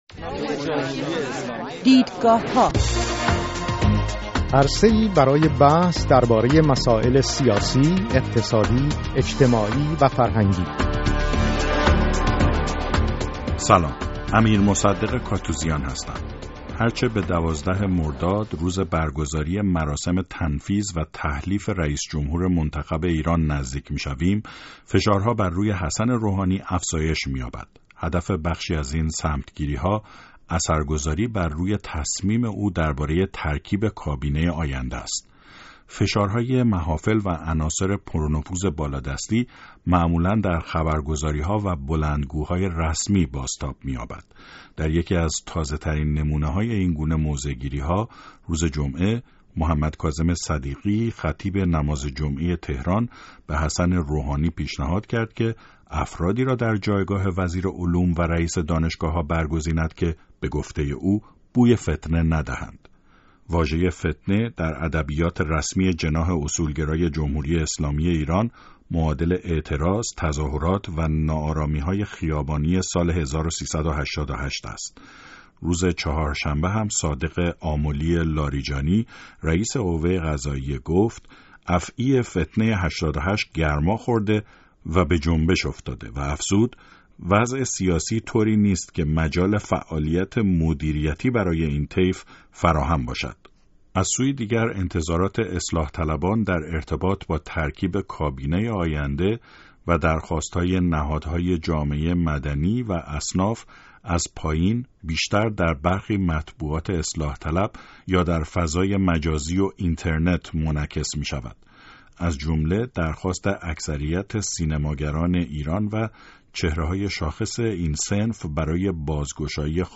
در برنامه این هفته «دیدگاهها» در گفت و گو با سه صاحب نظر به ترکیب احتمالی کابینه حسن روحانی رئیس جمهوری منتخب ایران پرداخته ایم.